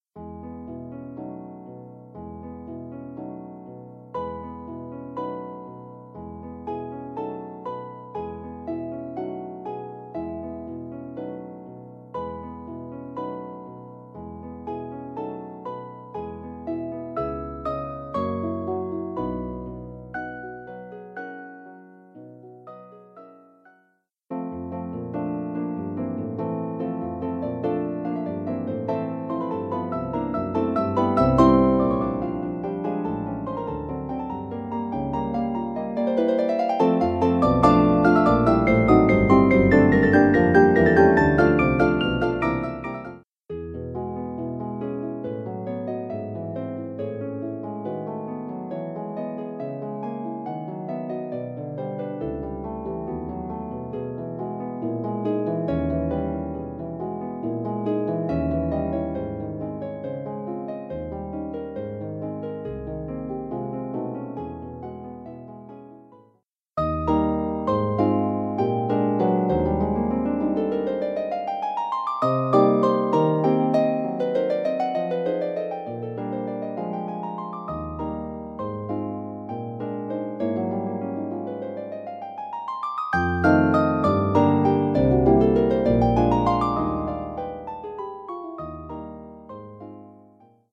for two pedal harps